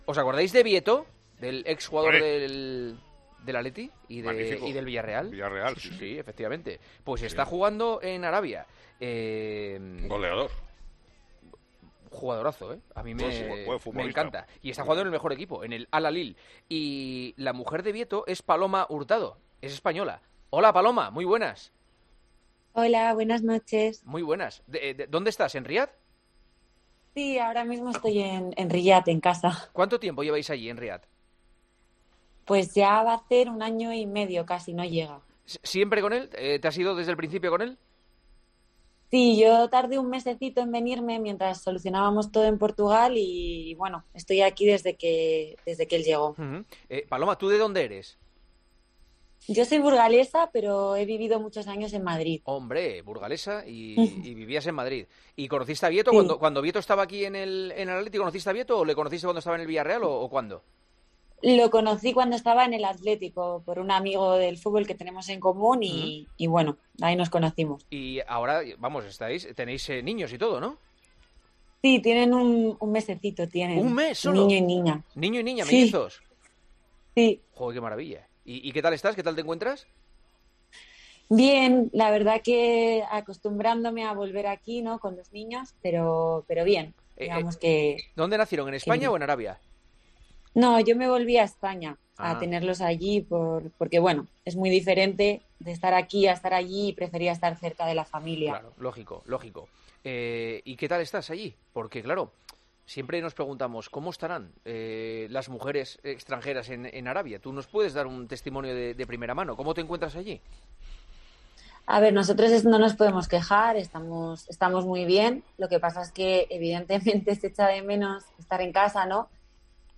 AUDIO - ENTREVISTA